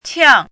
chinese-voice - 汉字语音库
qiang4.mp3